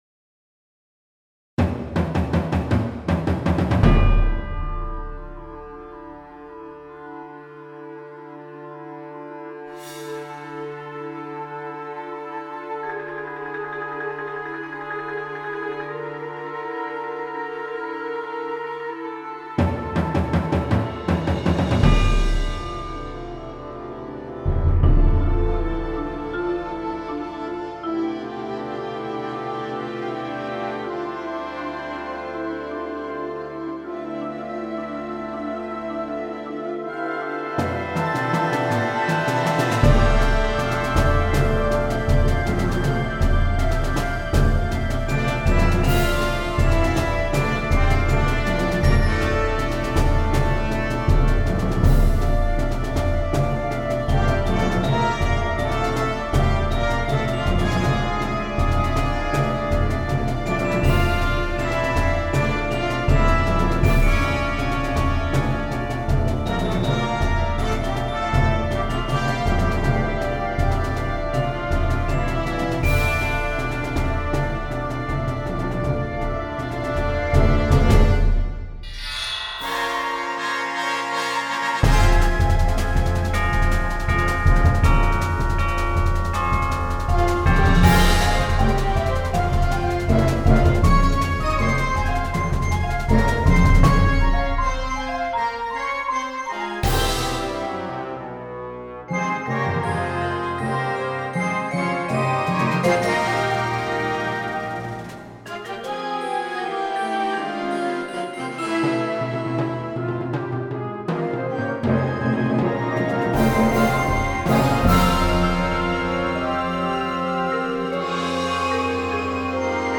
Genre: Band
Chimes/Vibraphone